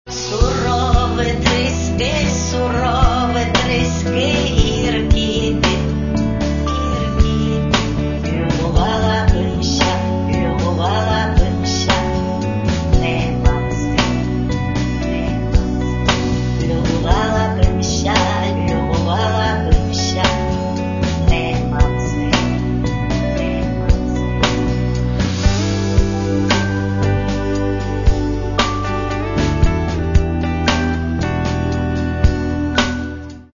Каталог -> MP3-CD -> Електроніка